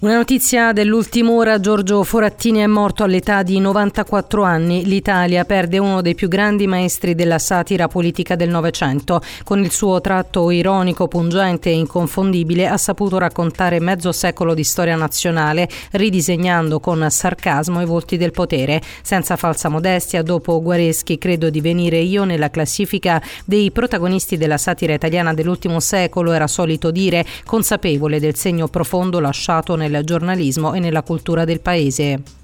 dal GIORNALE RADIO EDIZIONE DELLE 17.00